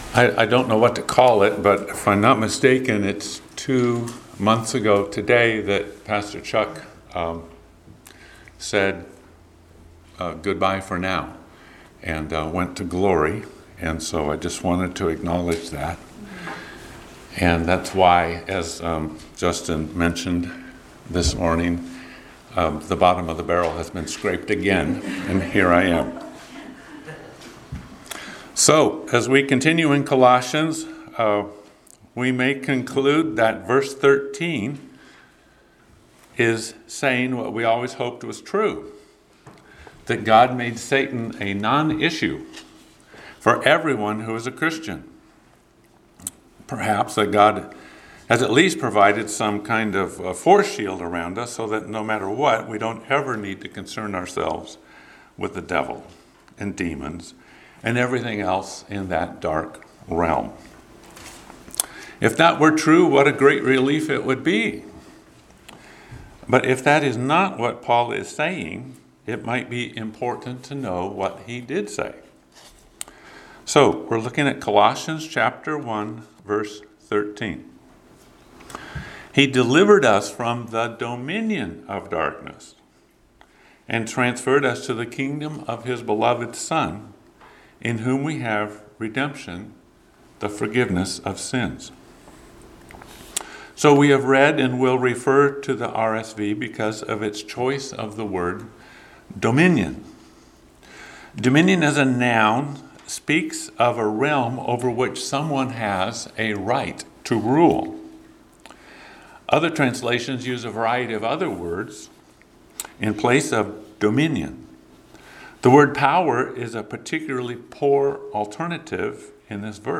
Passage: Colossians 1:13 Service Type: Sunday Morning Worship